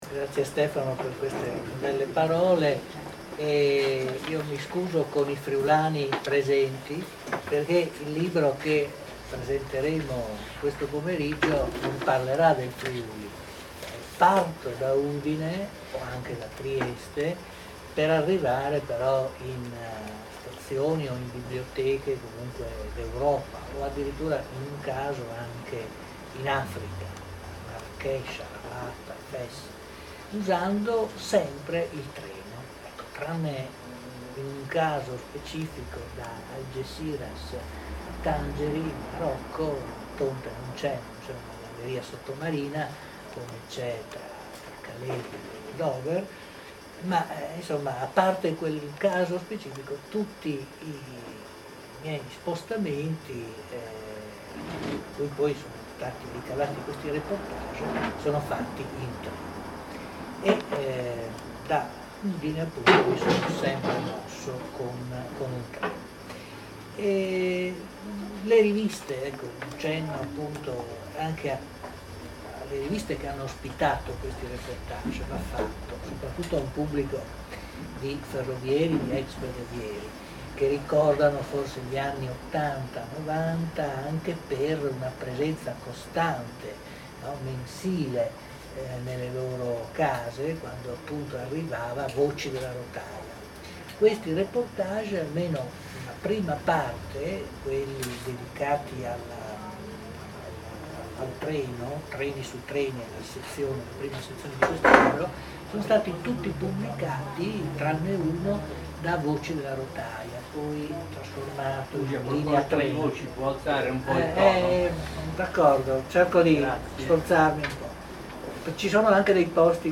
È disponibile la registrazione audio della presentazione del libro del 9 maggio 2014: